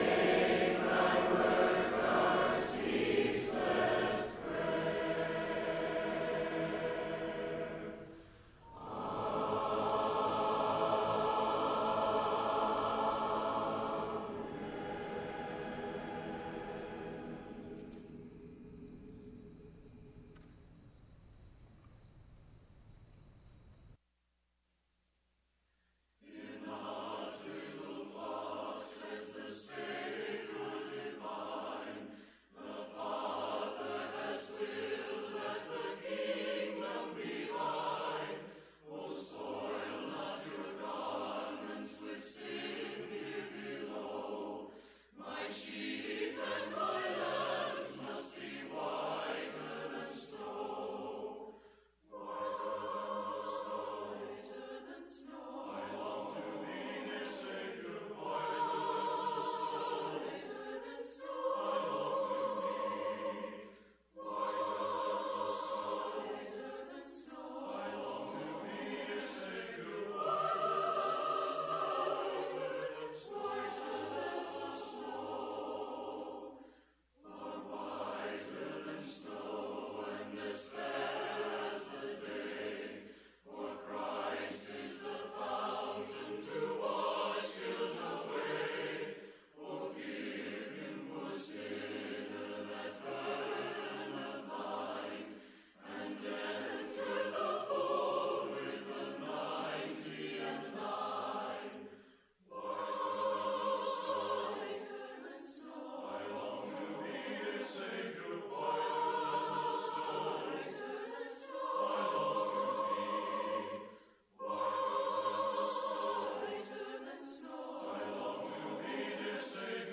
Proverbs 15:14, English Standard Version Series: Sunday PM Service